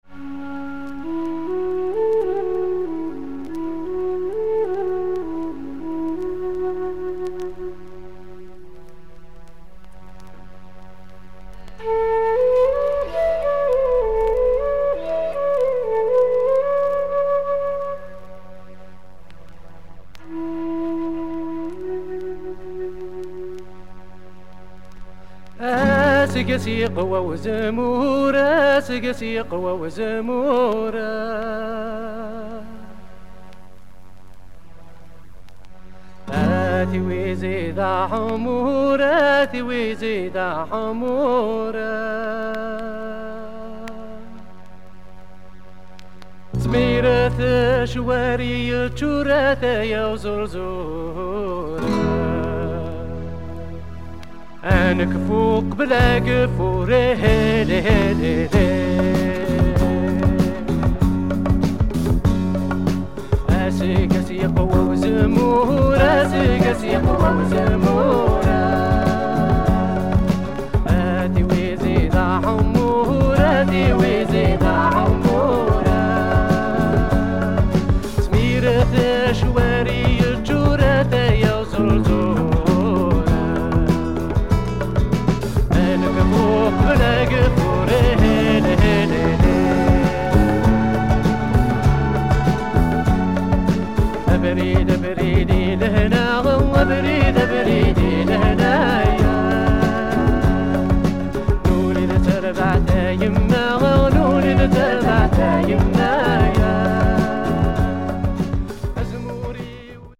Rare Algerian / Kabyle funk.